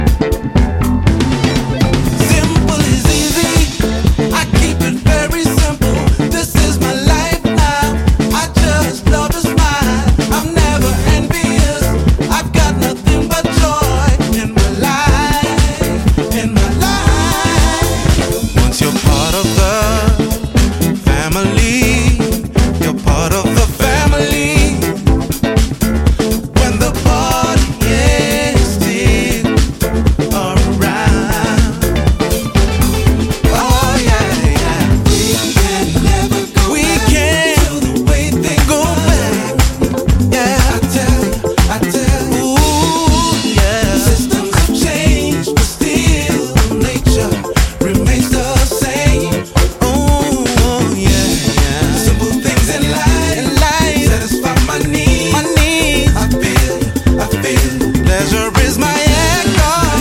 それぞれ8分の尺で温かいグルーヴのブギー/ファンクを展開